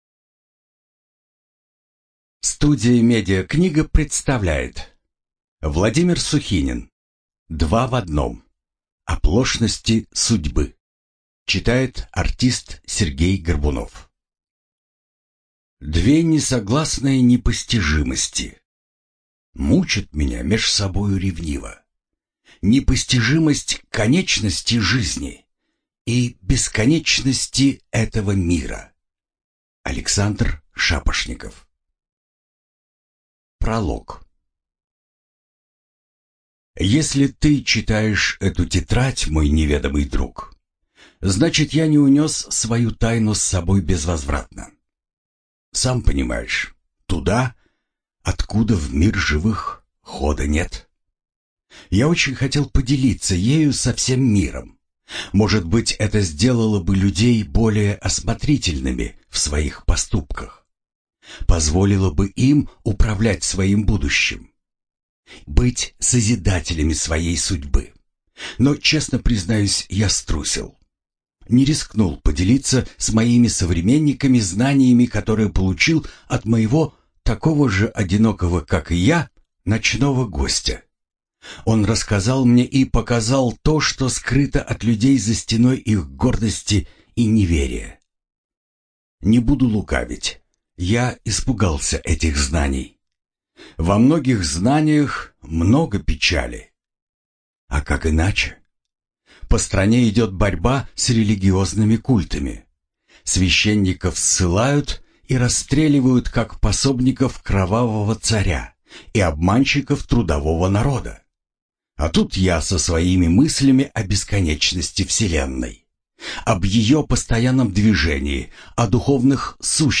ЖанрФантастика, Фэнтези
Студия звукозаписиМедиакнига